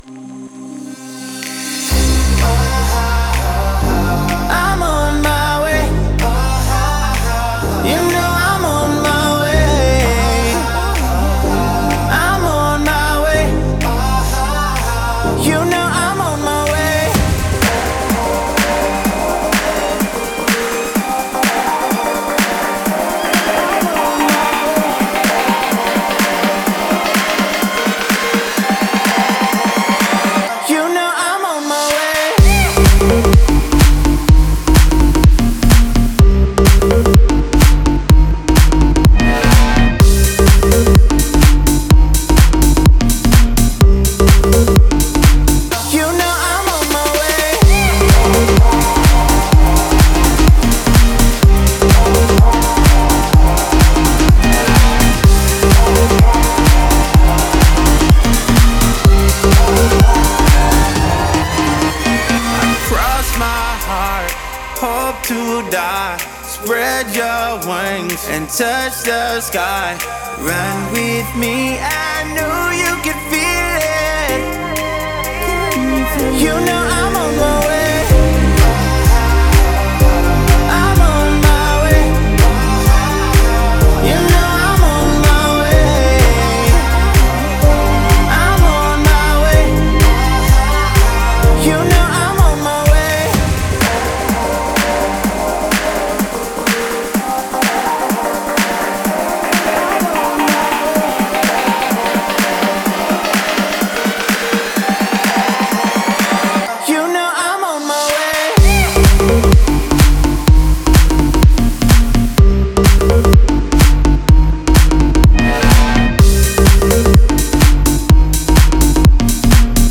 это зажигательная трек в жанре EDM